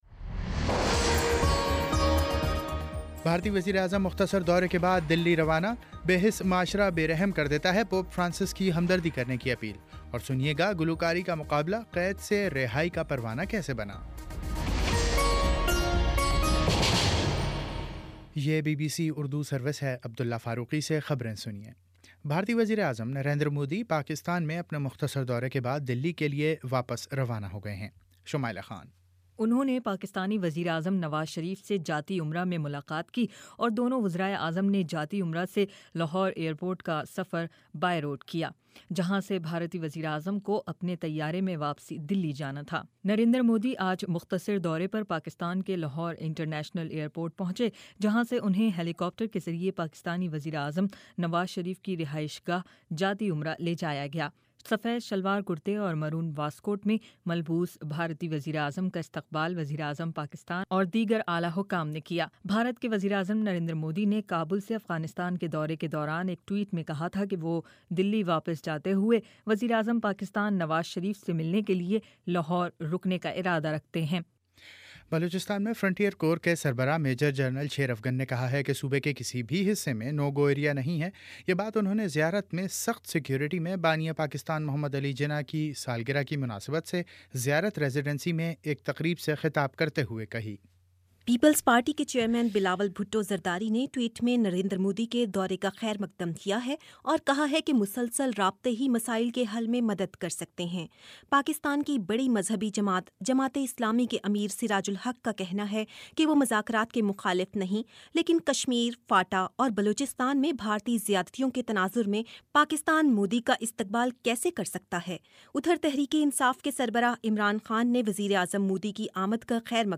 دسمبر 25: شام سات بجے کا نیوز بُلیٹن